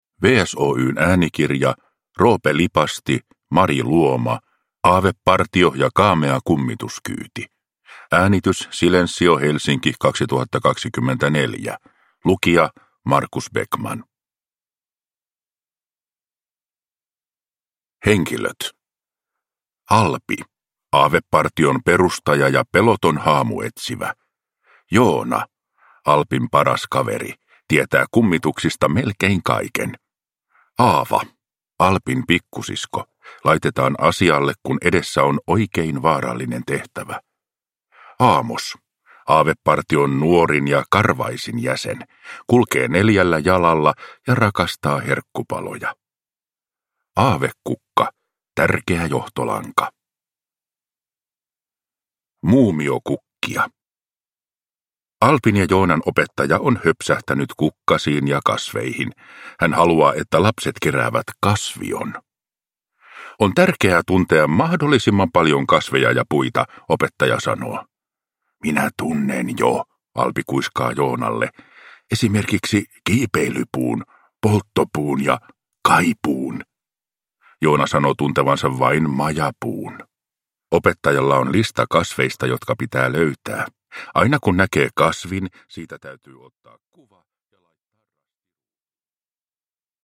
Aavepartio ja kaamea kummituskyyti – Ljudbok